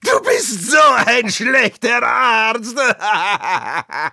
Voice file from Team Fortress 2 German version.
Spy_dominationmedic05_de.wav